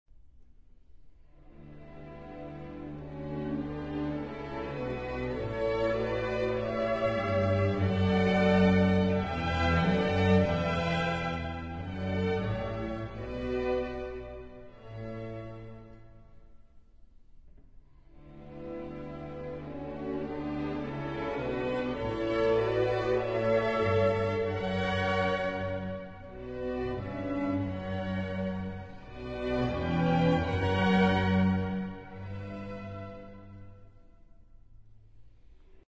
Larghetto elegiaco 9:34